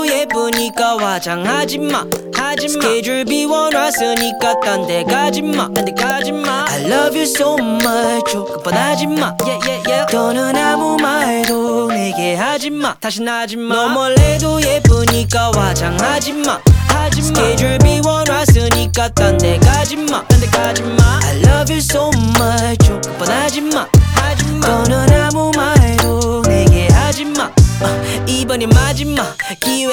Hip-Hop Hip-Hop Rap
Жанр: Хип-Хоп / Рэп